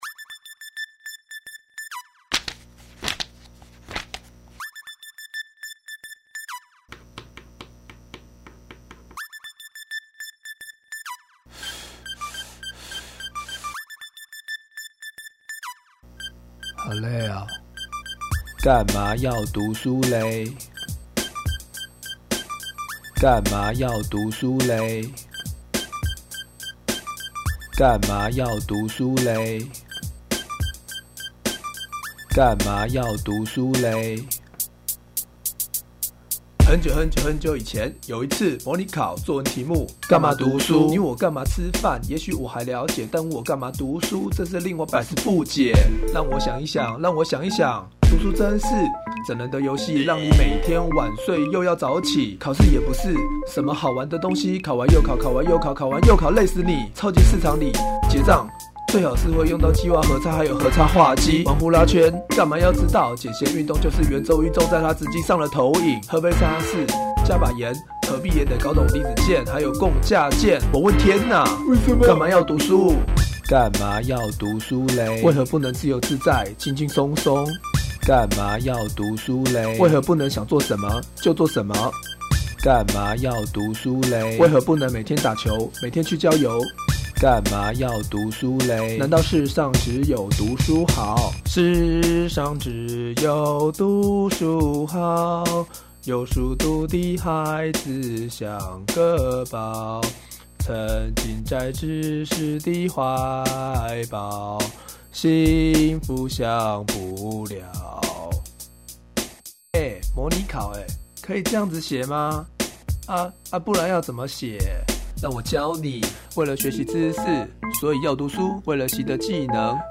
自編自唱的饒舌歌(2005.4) [歌曲下載]